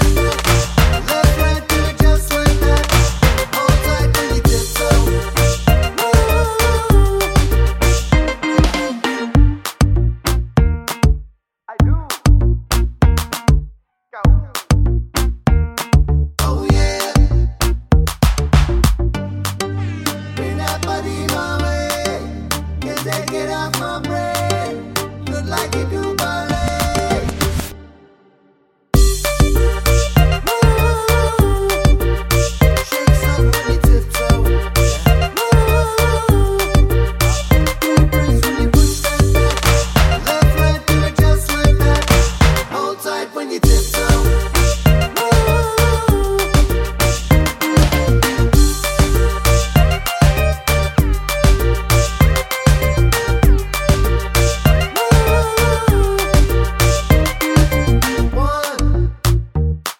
for duet R'n'B / Hip Hop 3:12 Buy £1.50